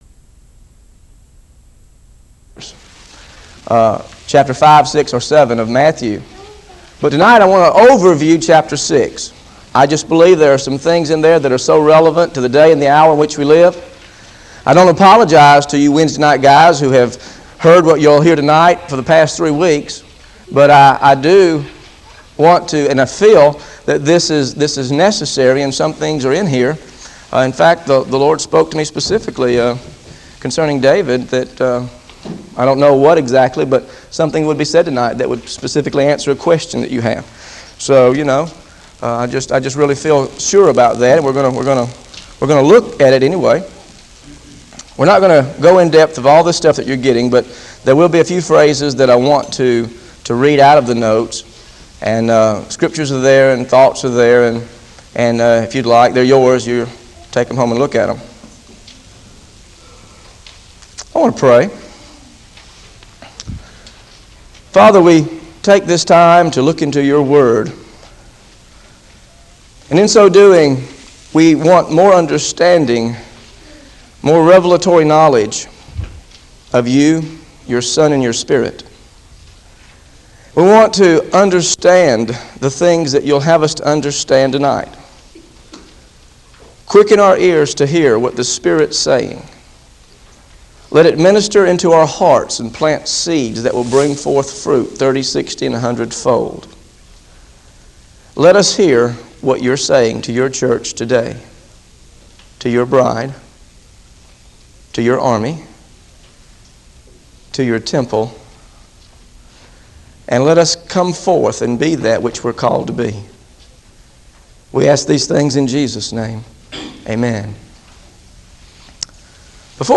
GOSPEL OF MATTHEW BIBLE STUDY SERIES This study of Matthew: Matthew 6 Overview How to Worship with Life Money Time is part of a verse-by-verse teaching series through the Gospel of Matthew.